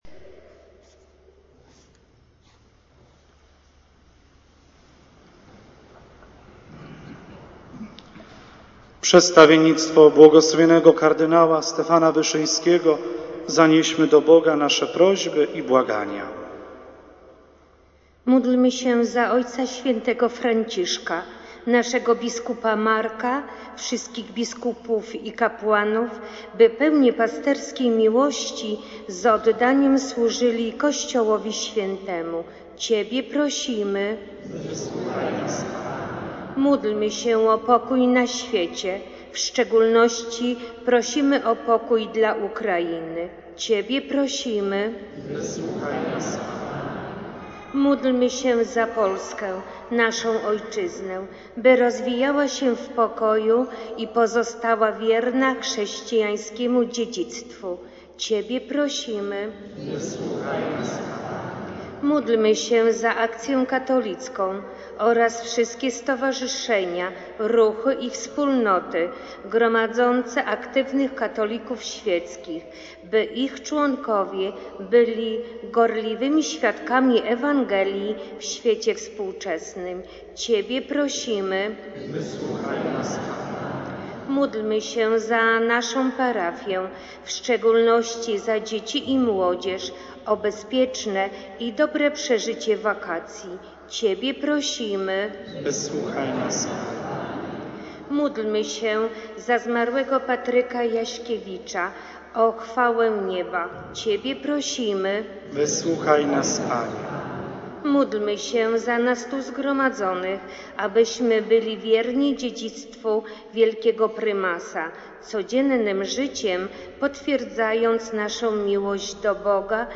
Modlitwa-wiernych.mp3